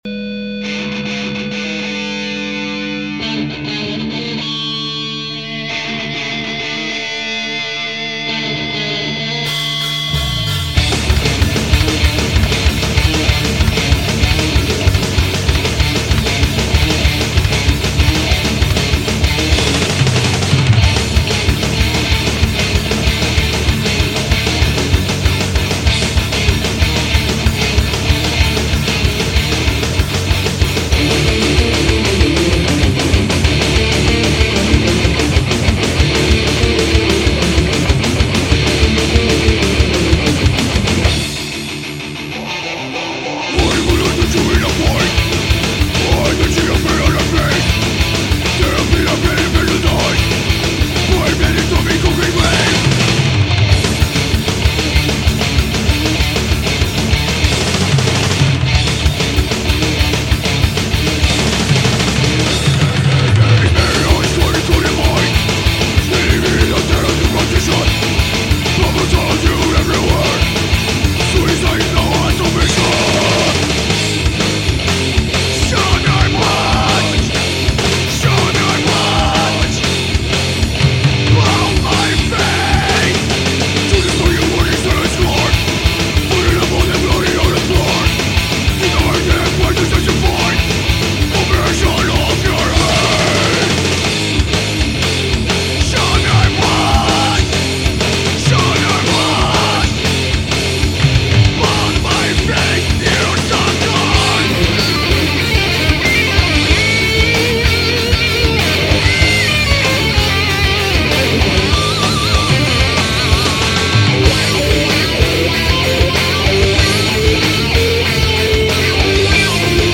EstiloThrash Metal